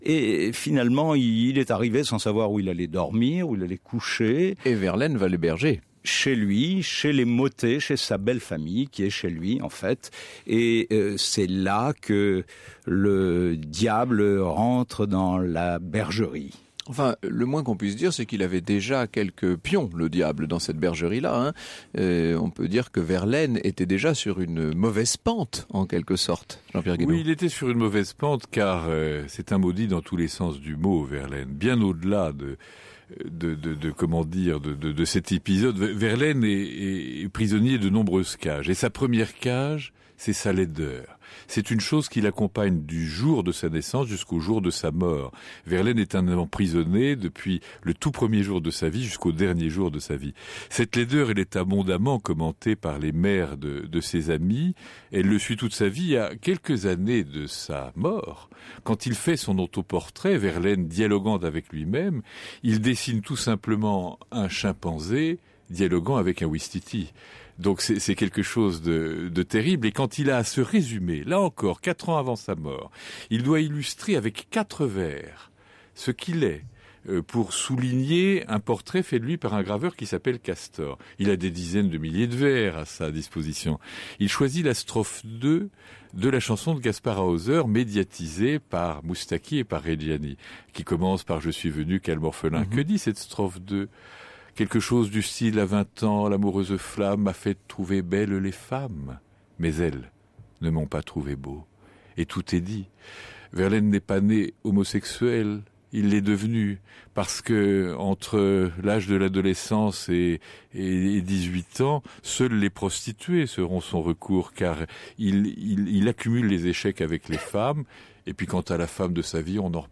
Et tous ces sourires intellos que l’on entend, ces allusions étranges qui paraissent expliquer que les deux hommes ne savaient pas vraiment ce qu’ils faisaient (ou alors c’est juste le pédéraste Rimbaud qui a détourné le gentil et amoureux Verlaine).
Ils finissent tout de même par lire des textes et expliquer que cette passion en était bien une, amoureuse, charnelle et littéraire.
Extrait de l’émission Au coeur de l’Histoire sur Europe 1 – Verlaine emprisonné – Franck Ferrand